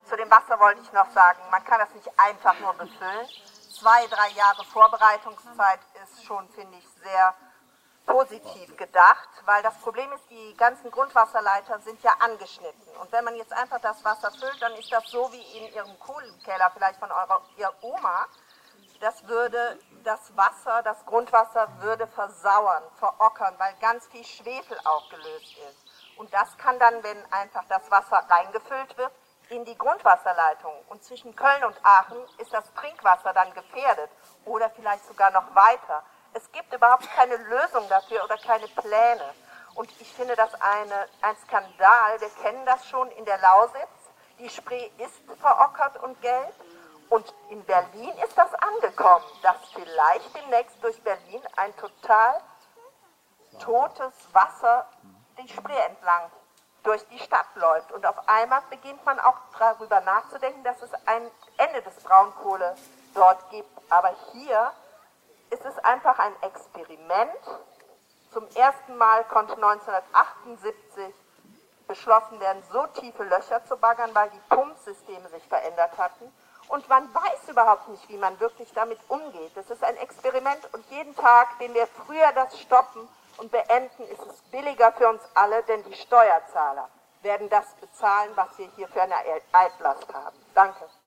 „Wald statt Kohle“ – 4 Jahre Waldführung im Hambacher Forst (Audio 3/9)